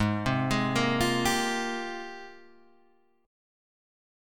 G#M13 chord